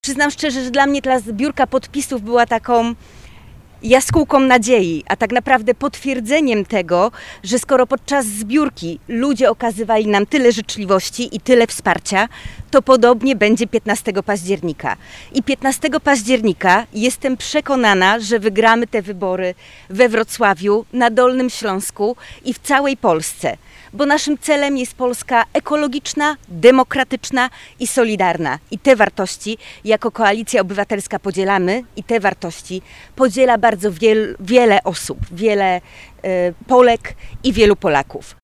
Konferencja prasowa odbyła się z udziałem wszystkich kandydatów i kandydatek KO.
-Zbieranie podpisów było wspaniałym doświadczeniem, mówi poseł Małgorzata Tracz (druga na liście w okręgu wrocławskim).